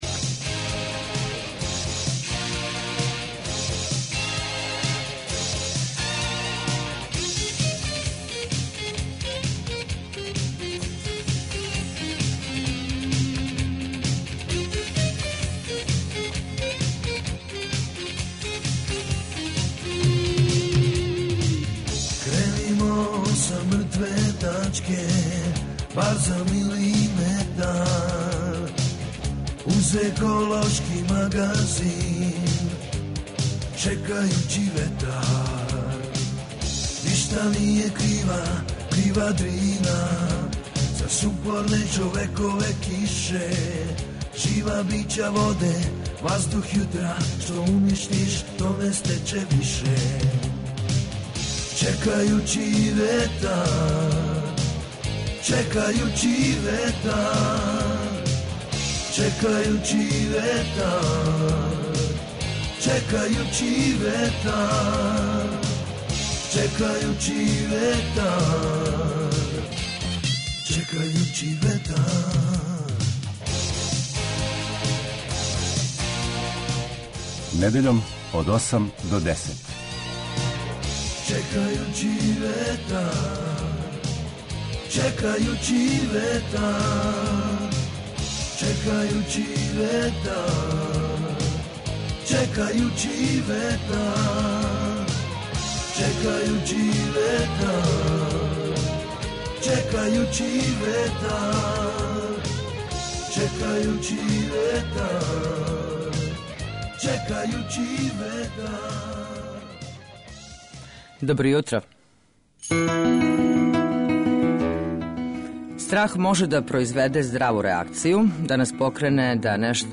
Еколошки магазин